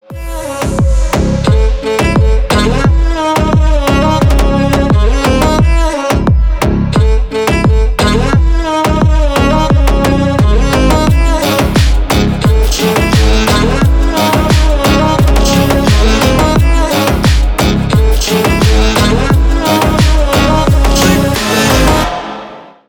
Танцевальные
громкие # без слов